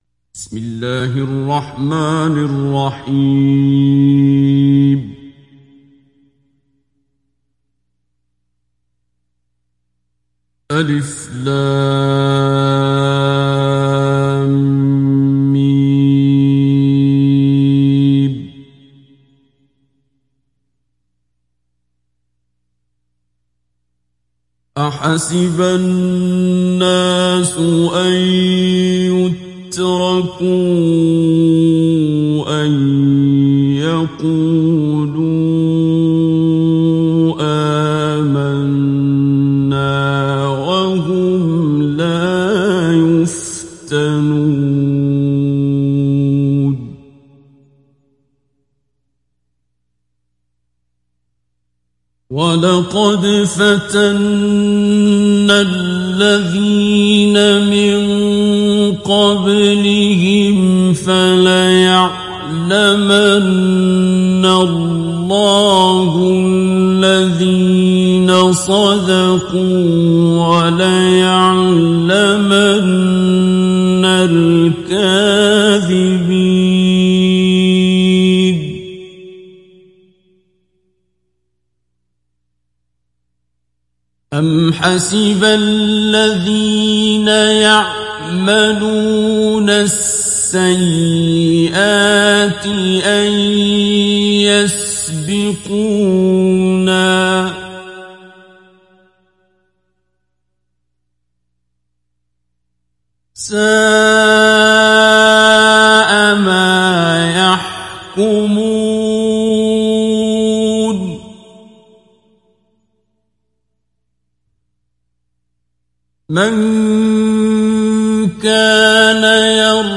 دانلود سوره العنكبوت عبد الباسط عبد الصمد مجود